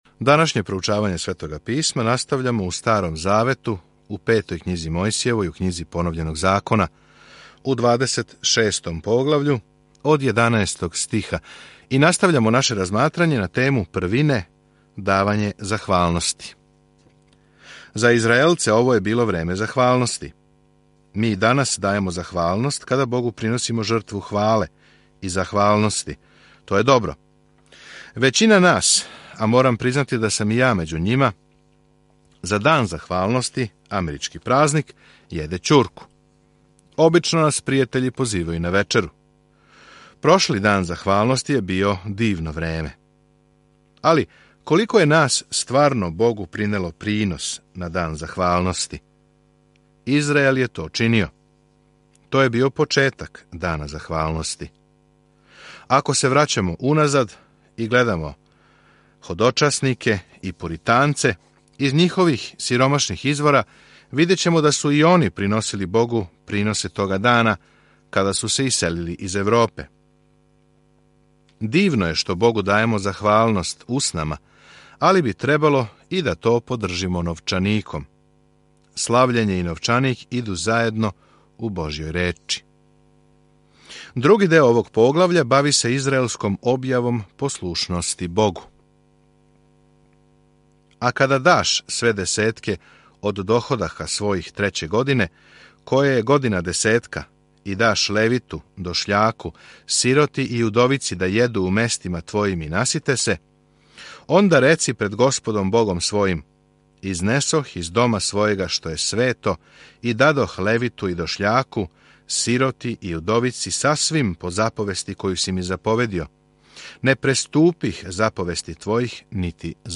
Mojsijeva 28:1-48 Dan 16 Započni ovaj plan čitanja Dan 18 O ovom planu Поновљени закон сажима добар Божји закон и учи да је послушност наш одговор на његову љубав. Свакодневно путујте кроз Поновљени закон док слушате аудио студију и читате одабране стихове из Божје речи.